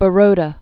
(bə-rōdə)